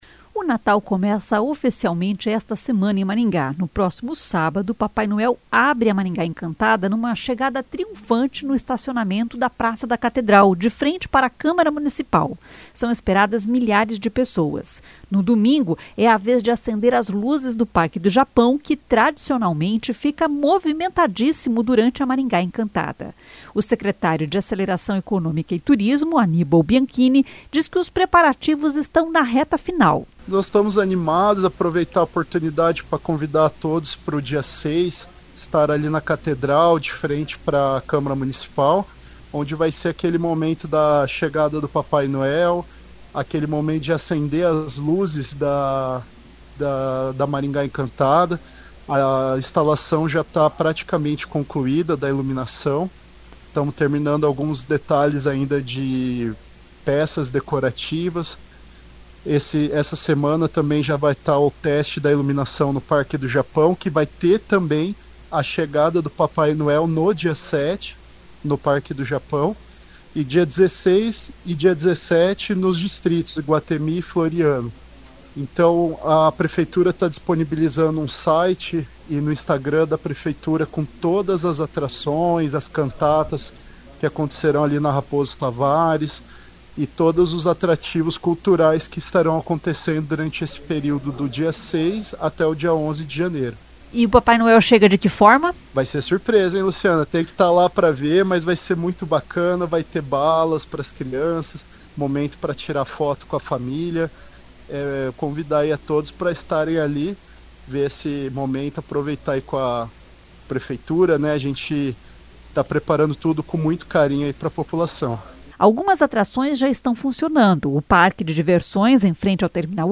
O secretário de Aceleração Econômica e Turismo, Annibal Bianchini, diz que os preparativos estão na reta final.